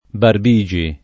[ barb &J i ]